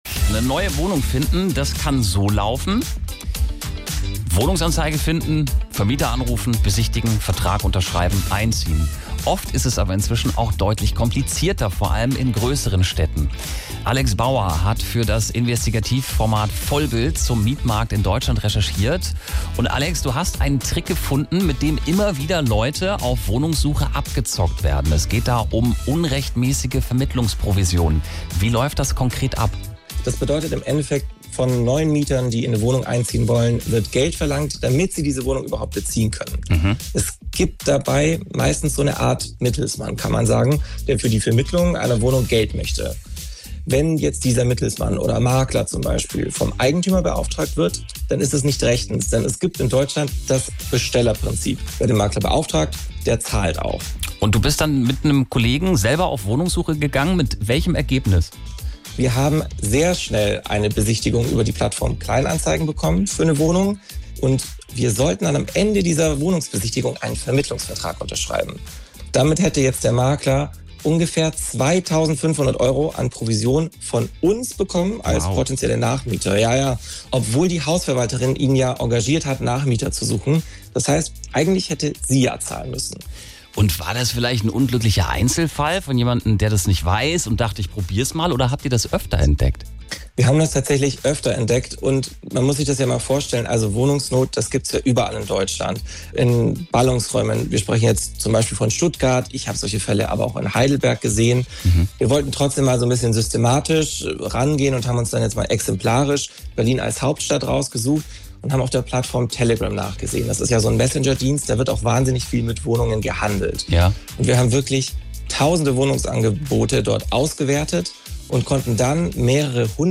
SWR3 Interview